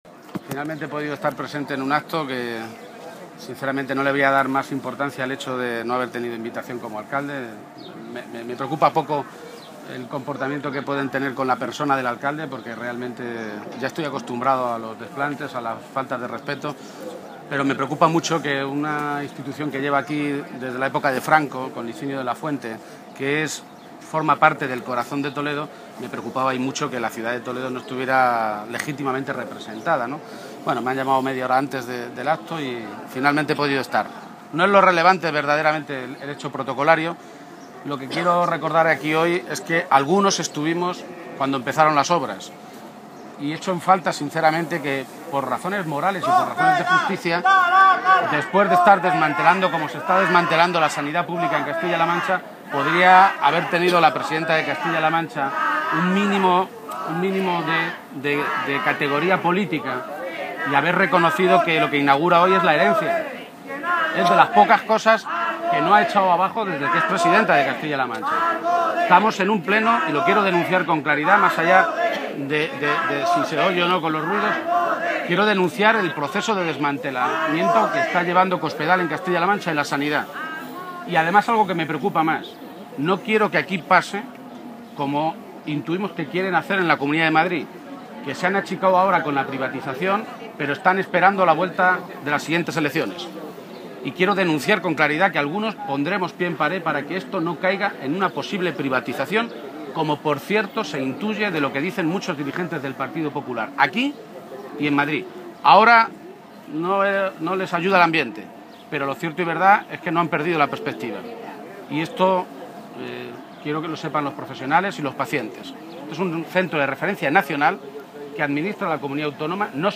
García-Page se pronunciaba de esta manera esta mañana, a preguntas de los medios de comunicación tras ese acto, y añadía que los socialistas de Castilla-La Mancha «vamos a poner pie en pared y vamos a combatir y a evitar cualquier intento de privatización del sistema sanitario público en la región».